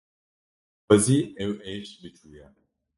Pronounced as (IPA) /eːʃ/